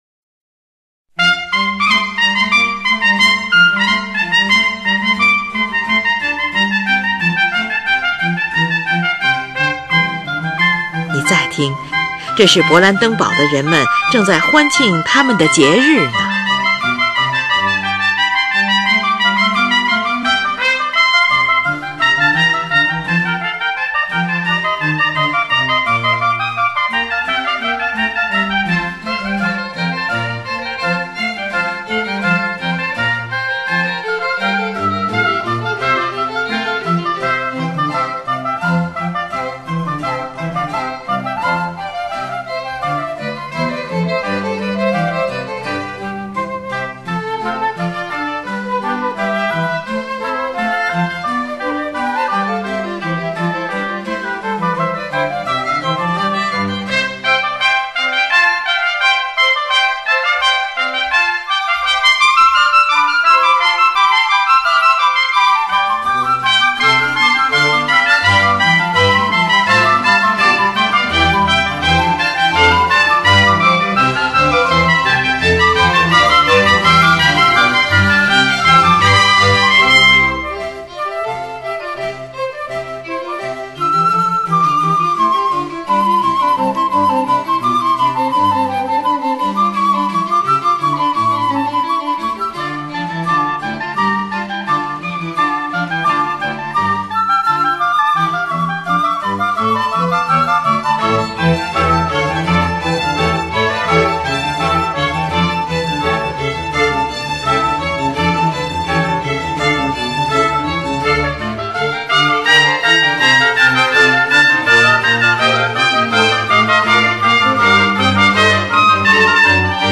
协奏曲，乐队协奏曲（大协奏曲），一般指为同类独奏乐器（如管乐器类）而写作的乐队作品。
快板，F大调，2/2拍子，呈现出兴高采烈的富于节奏感的主题